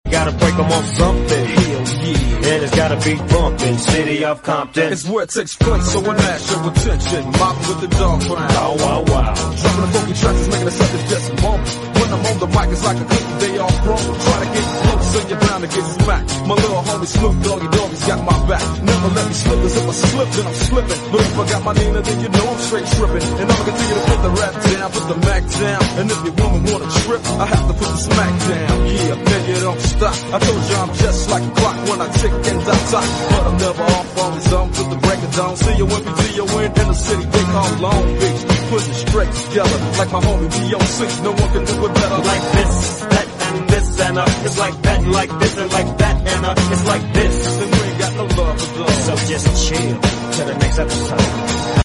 Heute mit dem Trabant mal das Treffen in Mieste besucht.